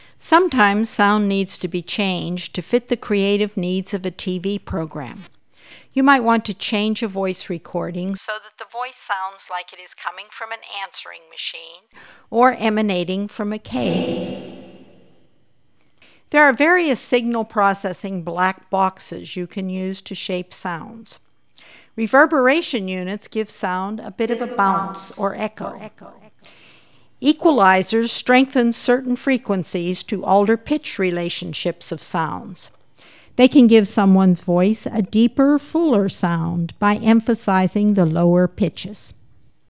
Shaping (7016.0K) – This is a voicer that talks about various effects as those effects are being executed on the voice.